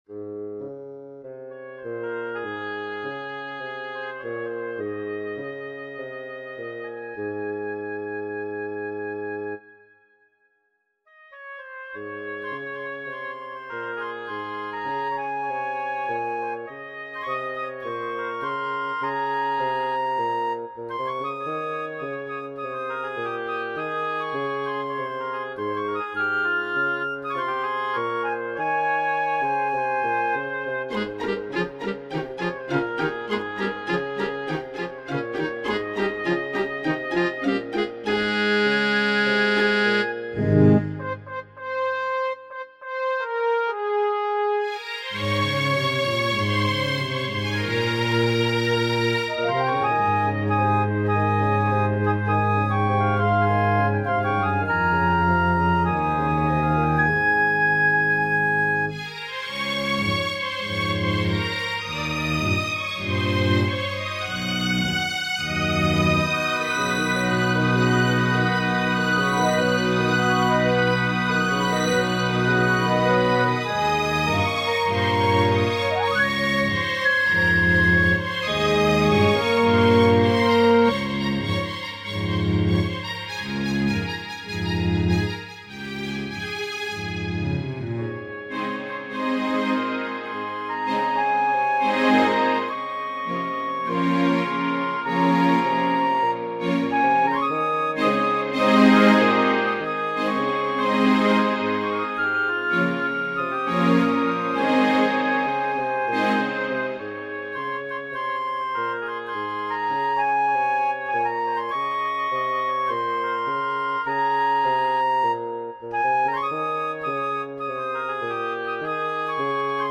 Okay, so it says a recorder playing a song, I've taken said song (Where Have all the Flowers Gone) and just composed a short piece - I wanted to make it my own so the song was literally used as inspiration, no more.